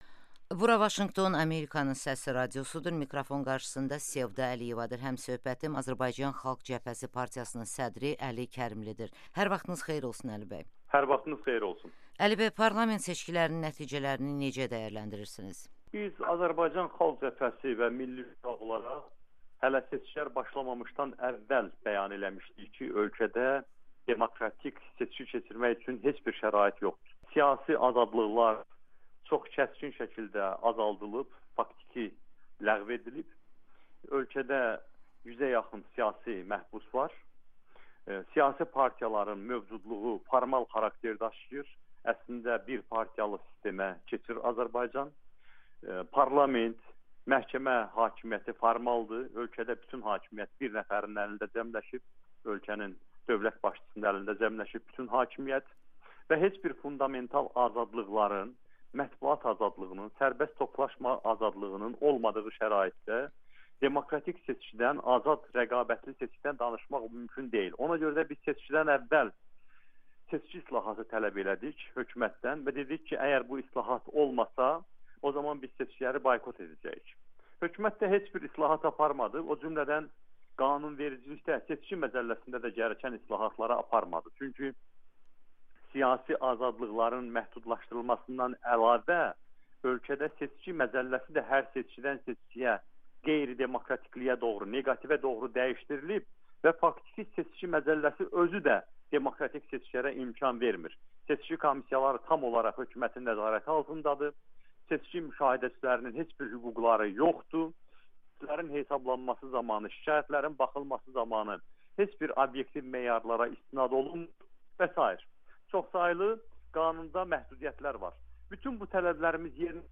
Əli Kərimli: Azərbaycan xalqı bir gün ayağa qalxıb, demokratiya, azadlıq, ədalət tələb edəcək [Audio-Müsahibə]